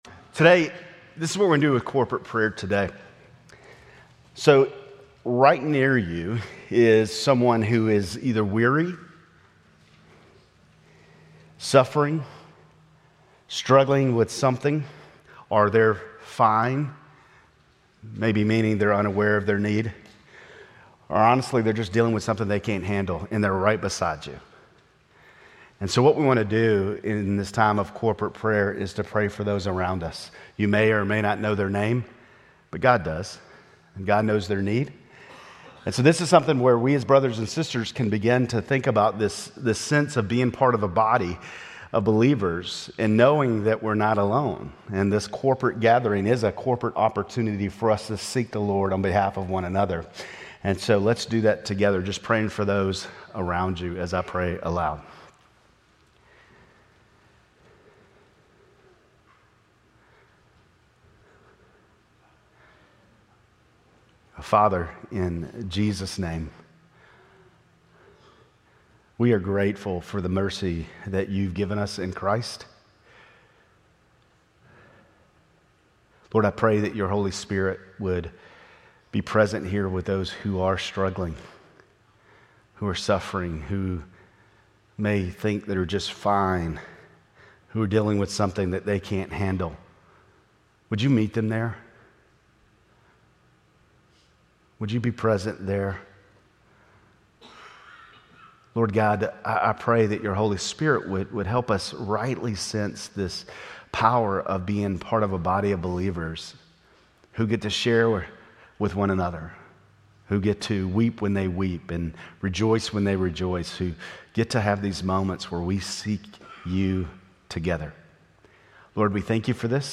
Grace Community Church Lindale Campus Sermons 10_19 Lindale Campus Oct 20 2025 | 00:38:39 1x 00:00 / 00:38:39 Subscribe Share RSS Feed Share Link Embed